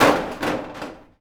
metal_sheet_impacts_15.wav